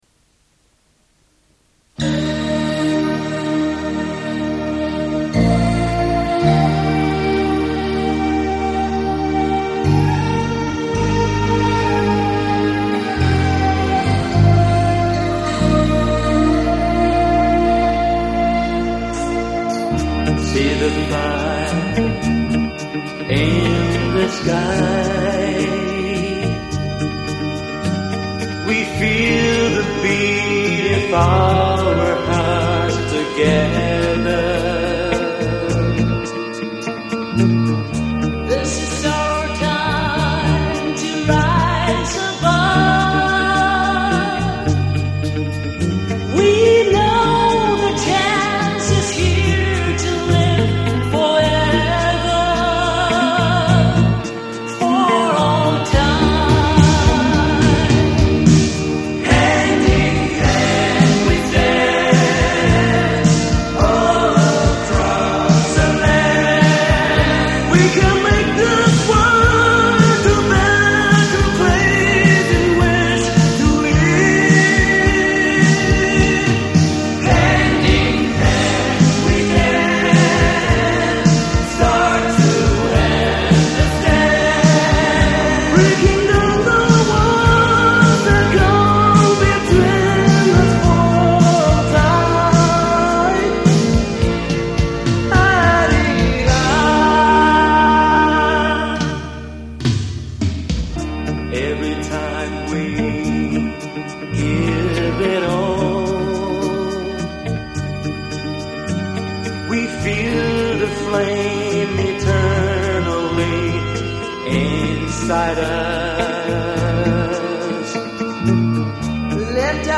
资源方式：磁带 录制WAV
演唱方式：英汉对照演唱